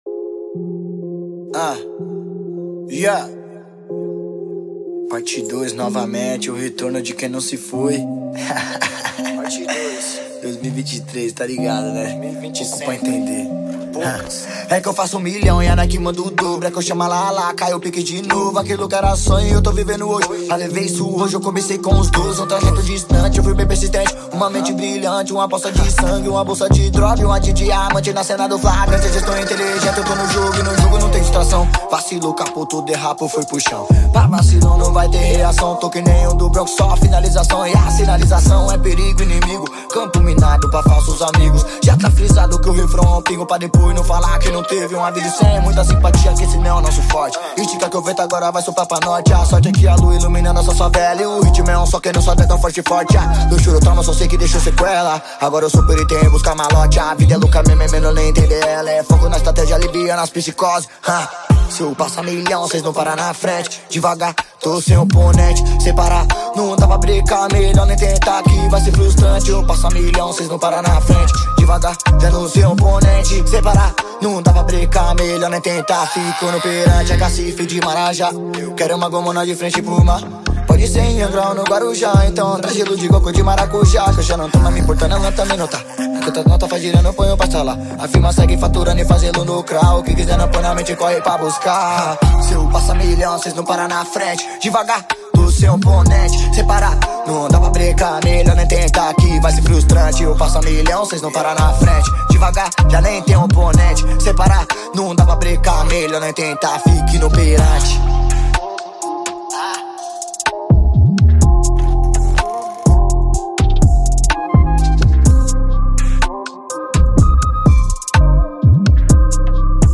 2024-02-24 03:05:06 Gênero: Funk Views